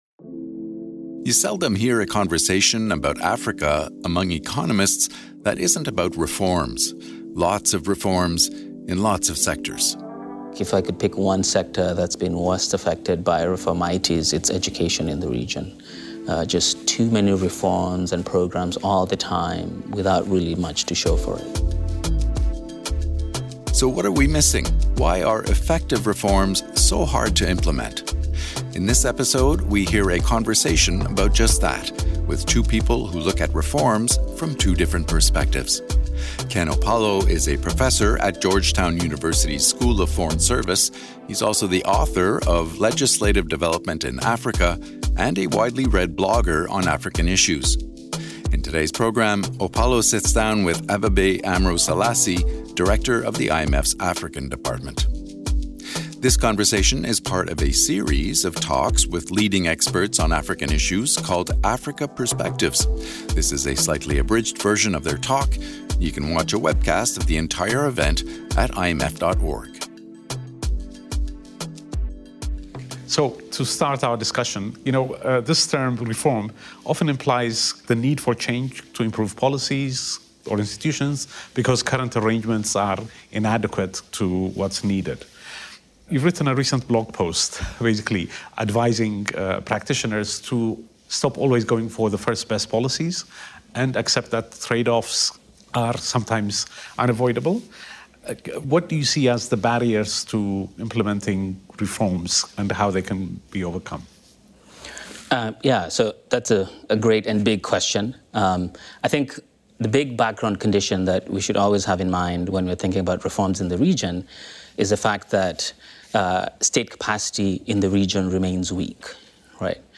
This conversation is part of a series of talks hosted by the IMF African Department called Africa Perspectives.